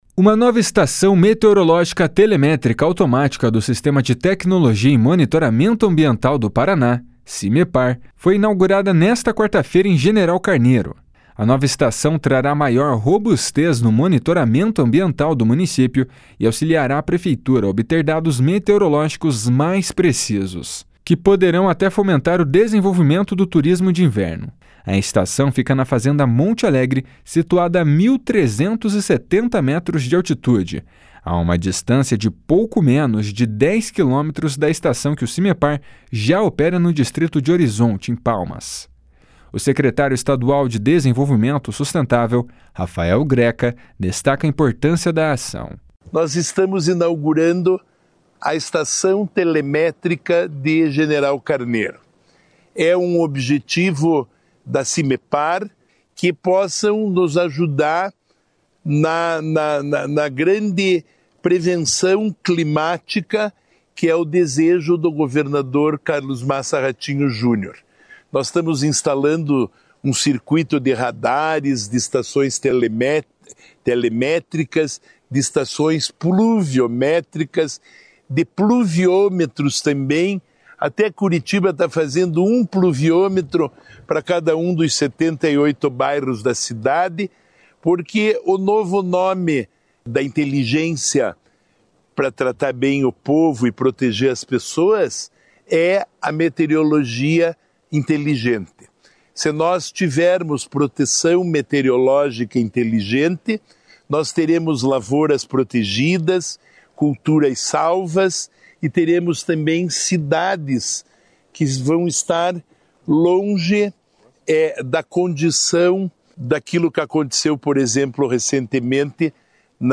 O prefeito de General Carneiro, Joel Ferreira, comemora a ação.